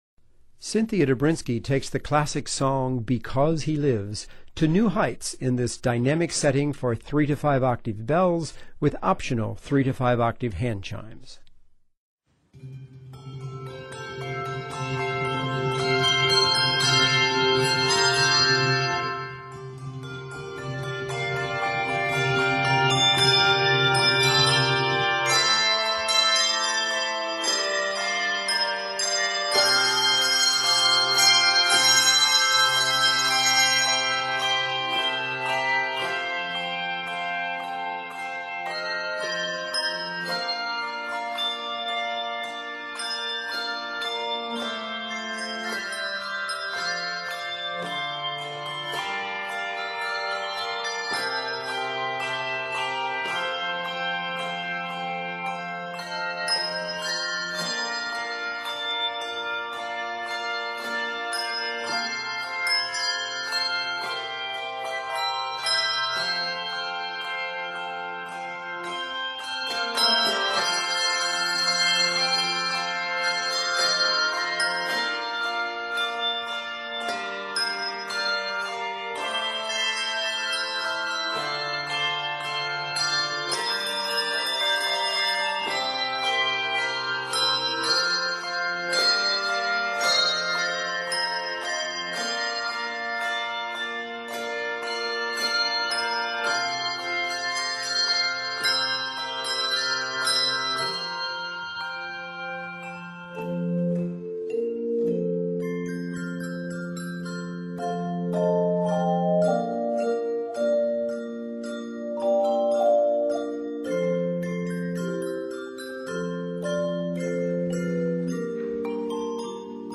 dynamic setting for handbells with optional chimes